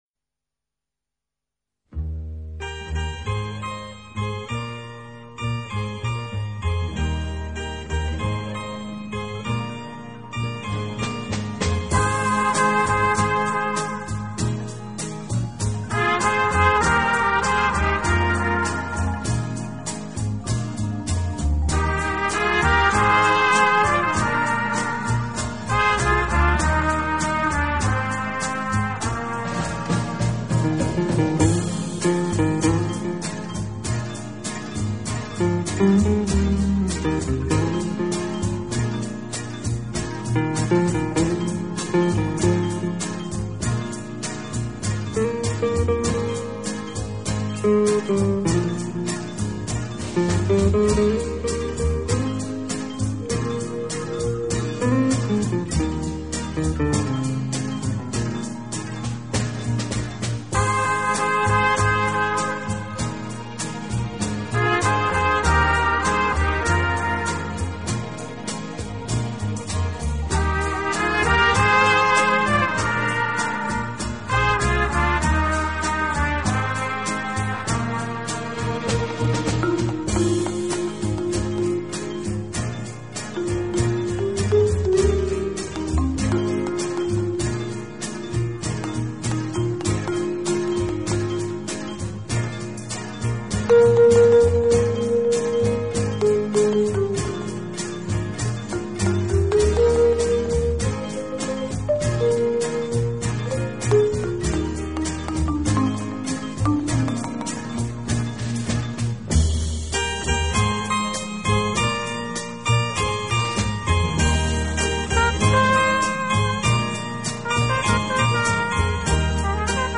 这张专辑运用了一些不常用的器乐，增加了神秘感和趣味感。